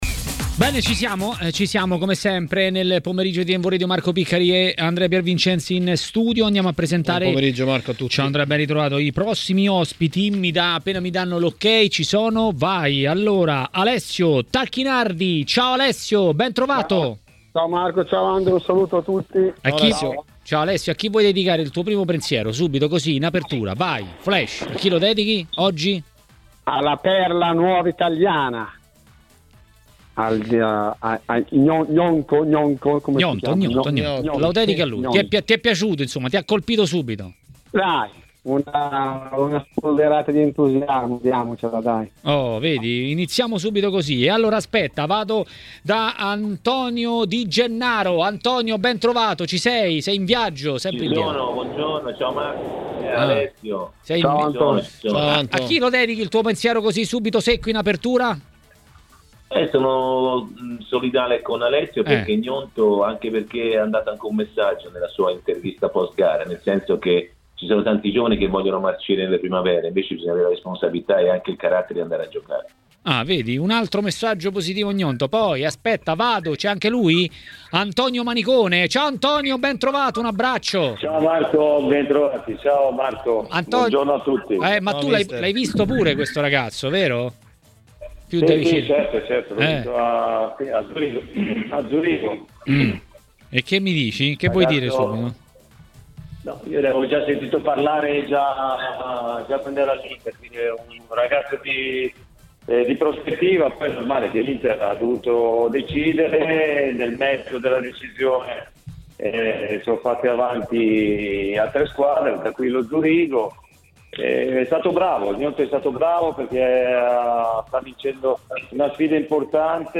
A TMW Radio, durante Maracanà, è arrivato il momento dell'ex calciatore e tecnico Alessio Tacchinardi.